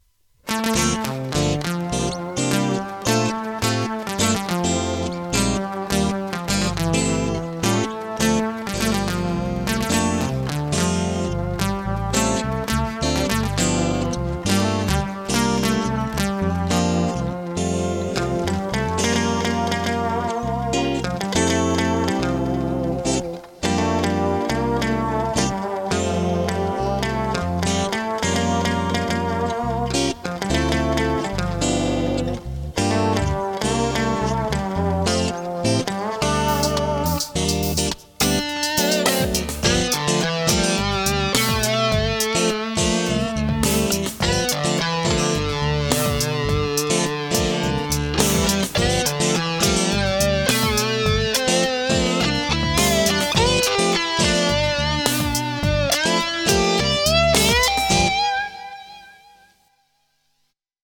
Background accompaniment performed on the described seven-string e.r.g. with chords and bass
Three different sounding melody and lead parts were all played on the fretless guitar (trackmix 2),
which also helped to get a more flowing watery sound effect during the last part.